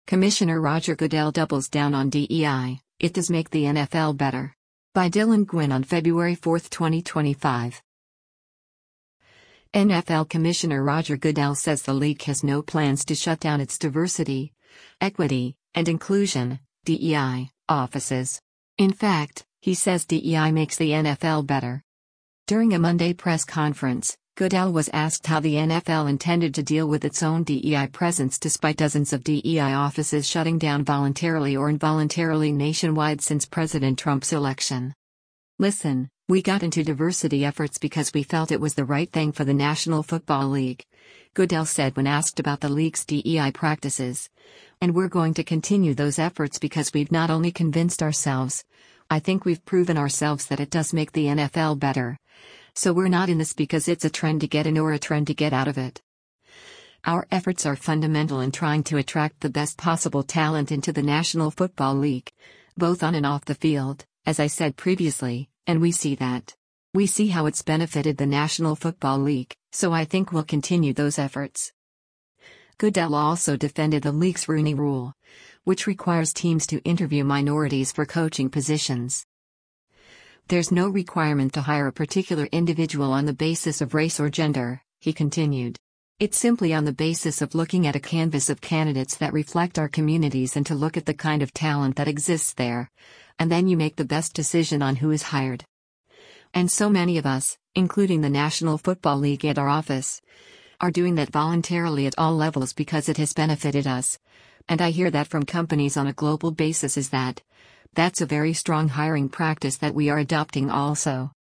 During a Monday press conference, Goodell was asked how the NFL intended to deal with its own DEI presence despite dozens of DEI offices shutting down voluntarily or involuntarily nationwide since President Trump’s election.